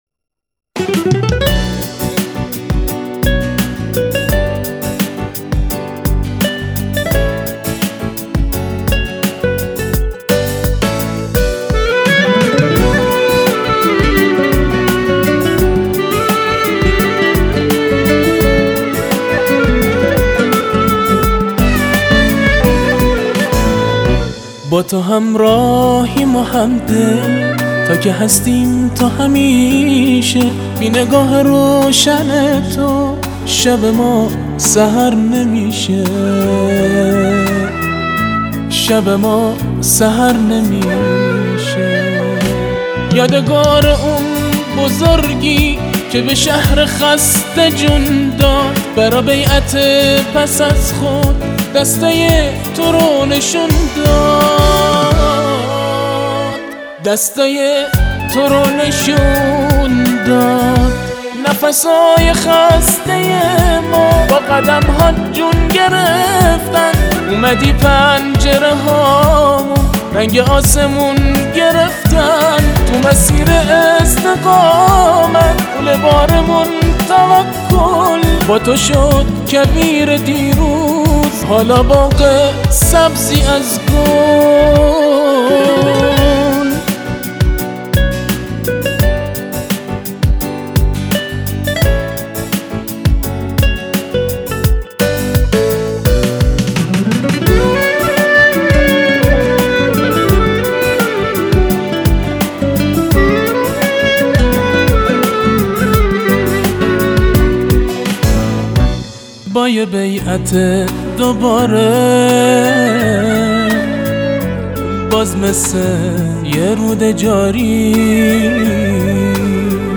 آلبوم آیینی ـ ارزشی